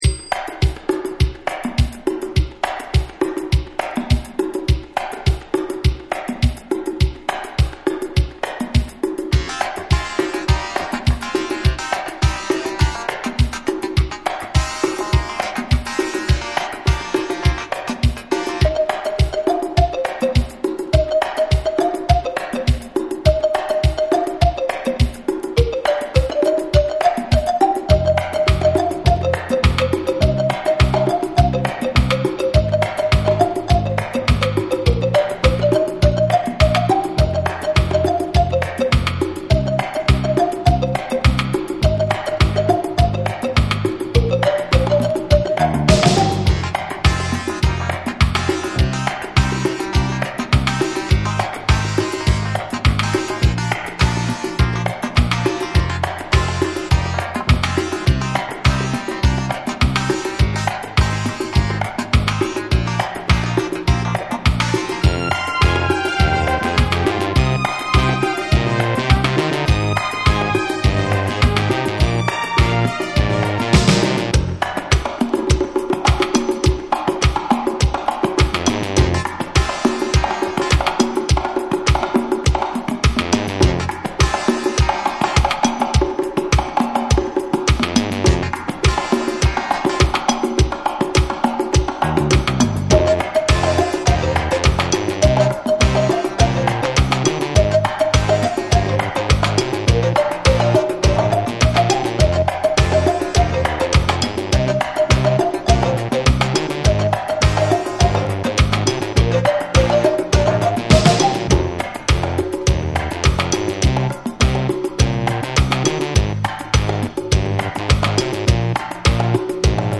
AFRO MIX